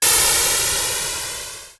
Machine22.wav